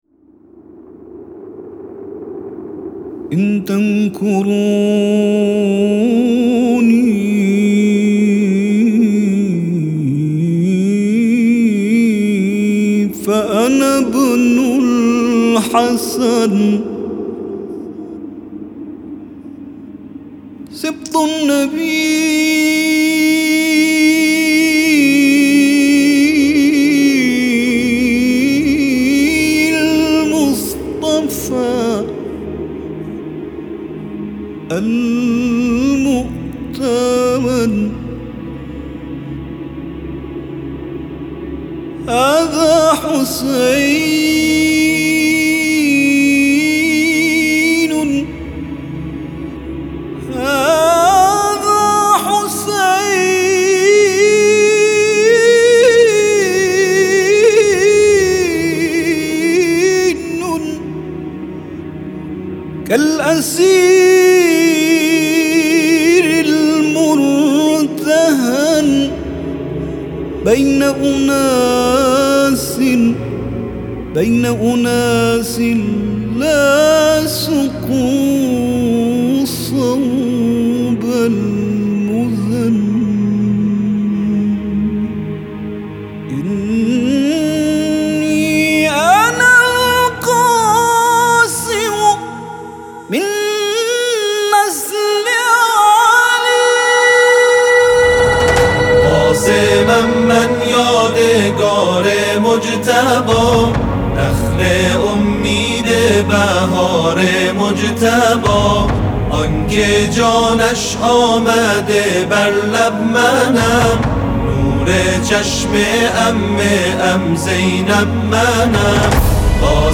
آلبوم عاشورایی
گروه بین المللی تواشیح و مدیحه سرایی